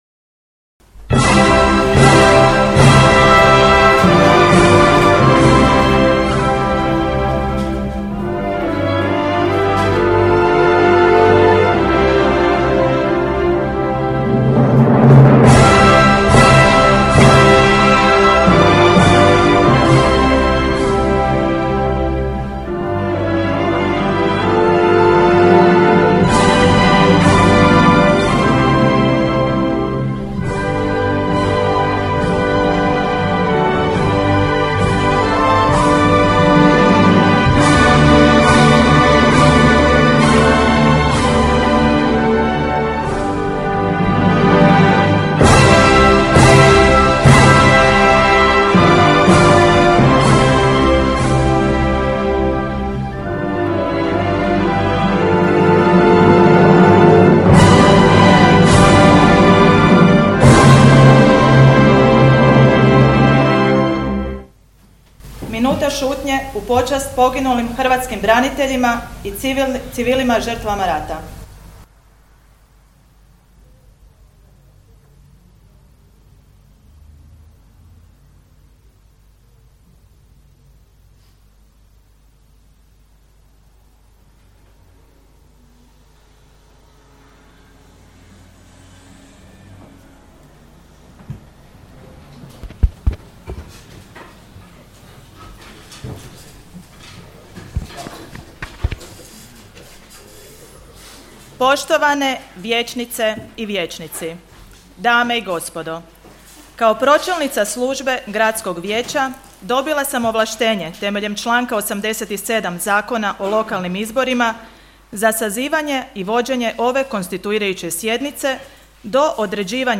Sjednice Gradskog vijeća – Grad Velika Gorica